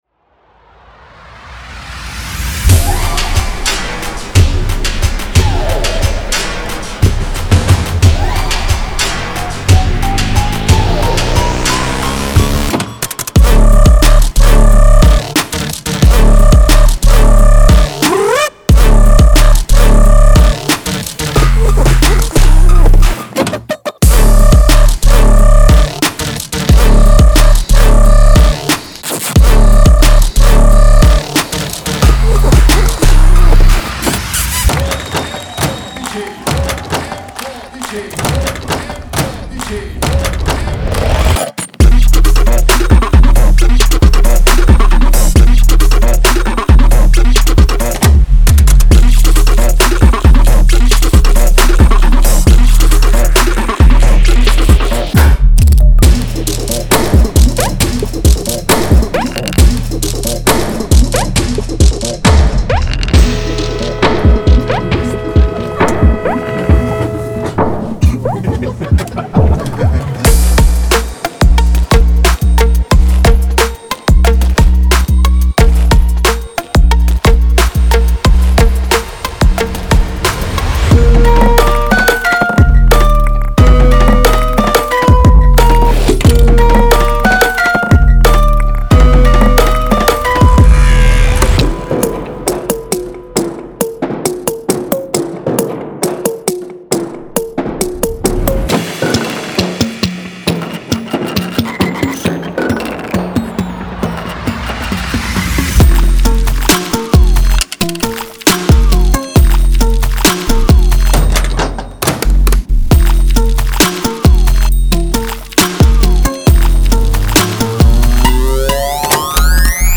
Techno，Future Bass，House，Drum&Bass，Chill，Moombah等各种类型的音乐。
在这里，您会发现创意打击乐，经过处理的人声，实验元素，纹理，一首单曲，旋律等等!-检查这个额
.085x Creative Percussions
.040xMouth Sounds
Fully Mixed And Mastered